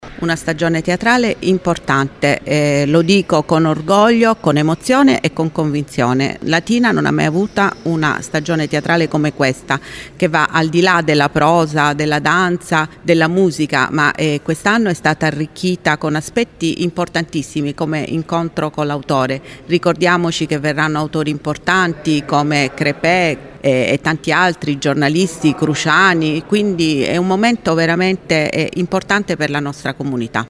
Un incontro carico di emozione e simpatia quello che si è tenuto oggi al foyer del Teatro D’Annunzio di Latina, dove Lino Banfi ha presentato in anteprima lo spettacolo che andrà in scena il 27 novembre, inserito nel cartellone della stagione teatrale 2025-2026 del teatro comunale.